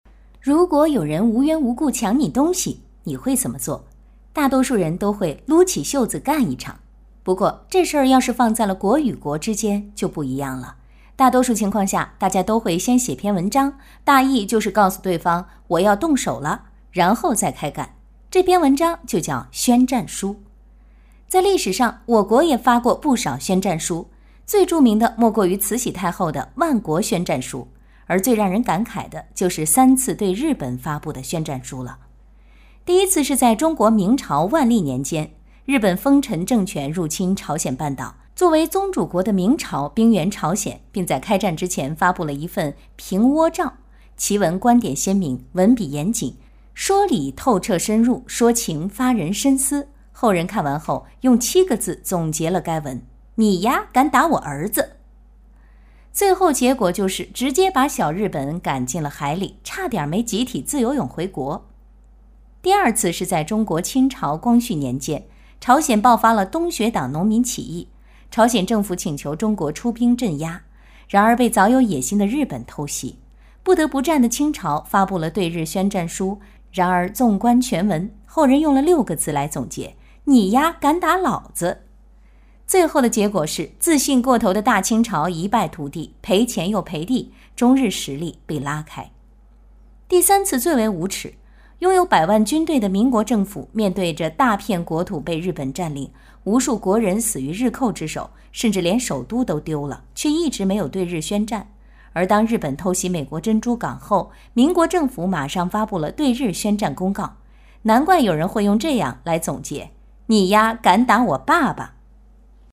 专题片/宣传片配音-纵声配音网
女16 历史自媒体解说（讲述）.mp3